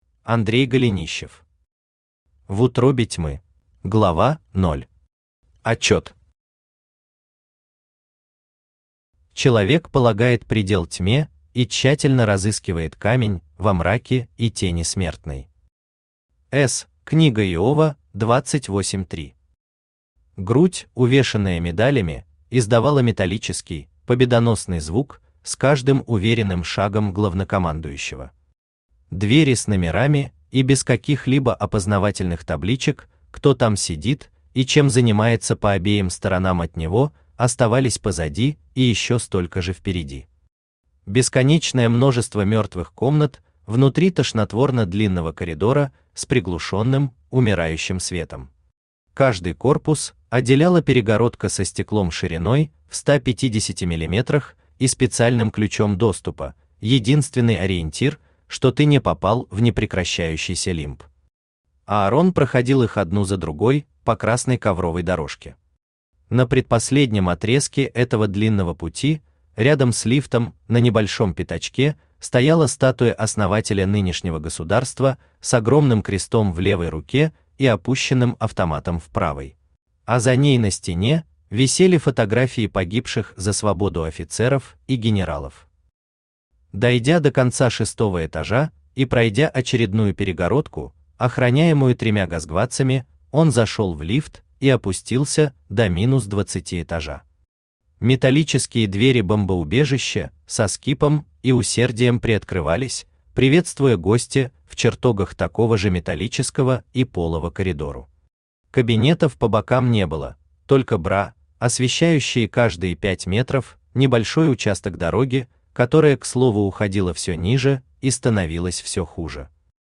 Aудиокнига В утробе тьмы Автор Андрей Валерьевич Голенищев Читает аудиокнигу Авточтец ЛитРес.